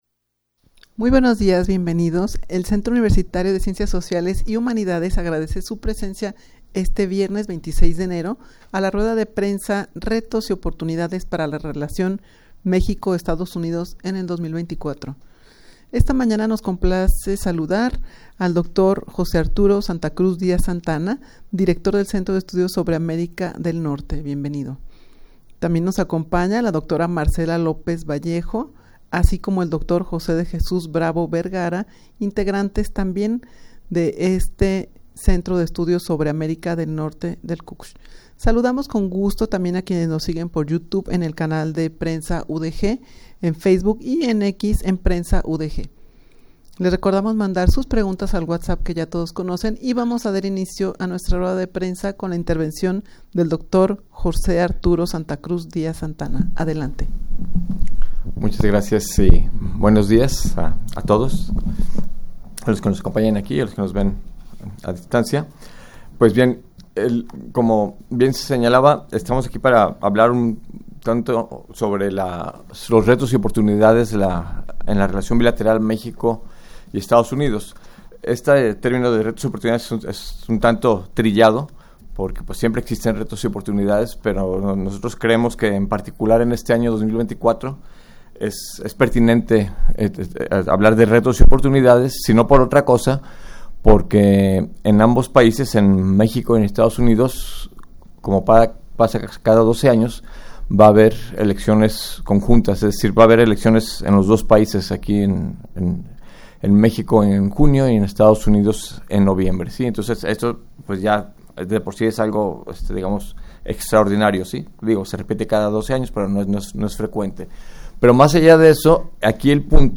rueda-de-prensa-retos-y-oportunidades-para-la-relacion-mexico-estados-unidos-en-2024.mp3